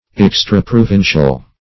Search Result for " extraprovincial" : The Collaborative International Dictionary of English v.0.48: Extraprovincial \Ex`tra*pro*vin"cial\, a. Not within of pertaining to the same province or jurisdiction.
extraprovincial.mp3